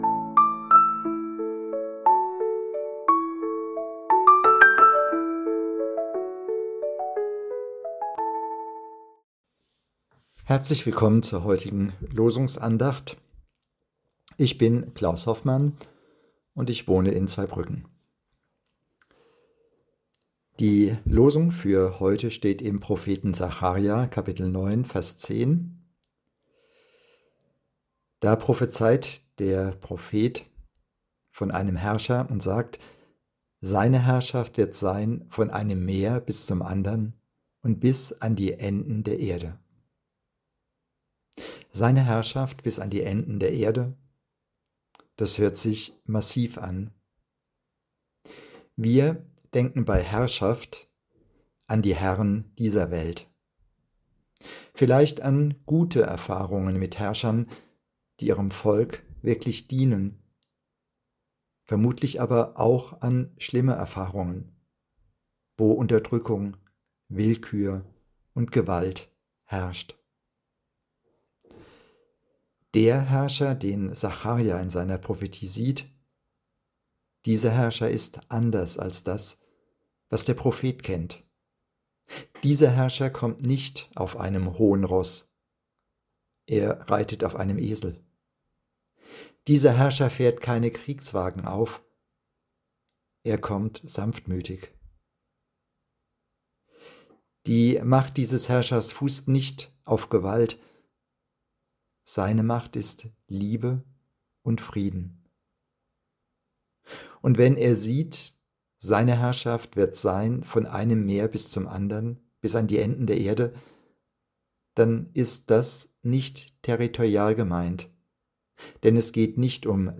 Losungsandacht für Montag, 20.10.2025